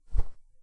玩家互动 " 打火机火焰
描述：打火机